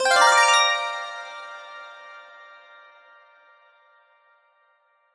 treasure_star_appear_01.ogg